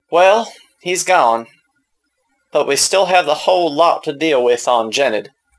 Human Male, Age 29